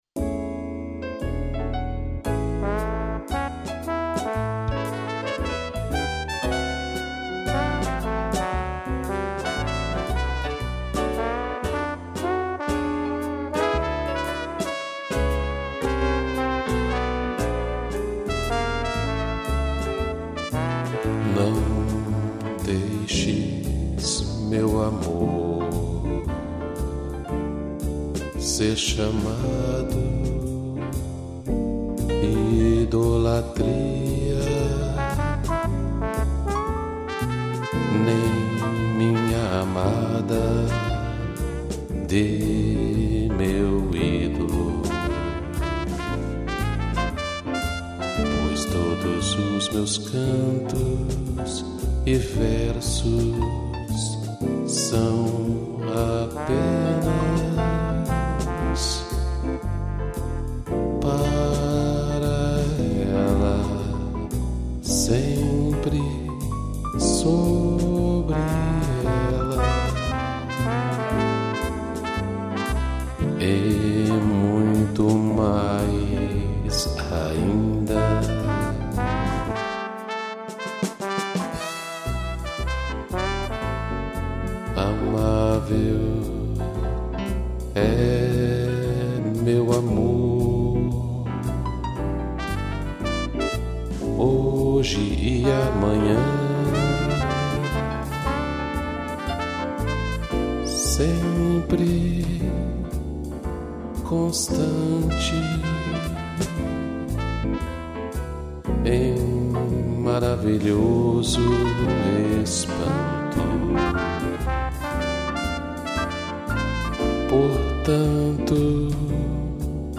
piano, trombone e trompete